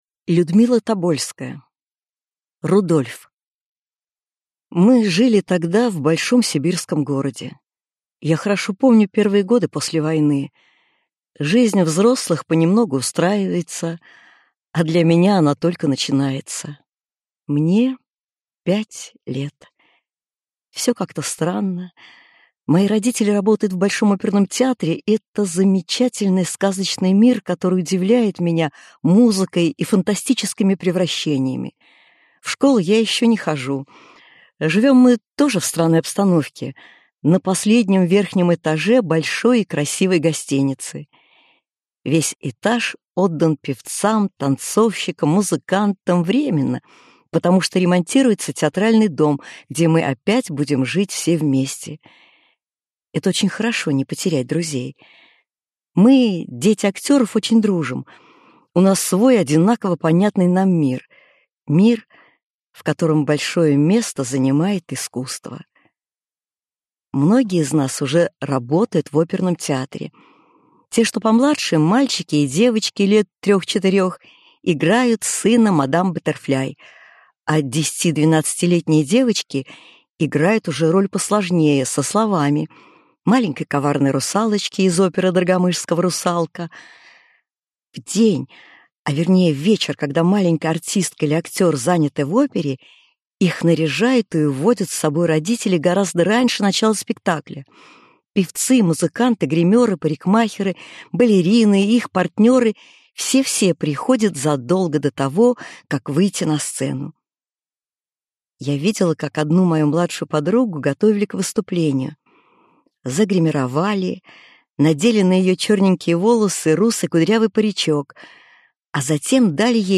Аудиокнига Взгляд с холма | Библиотека аудиокниг
Aудиокнига Взгляд с холма Автор Людмила Тобольская Читает аудиокнигу Актерский коллектив.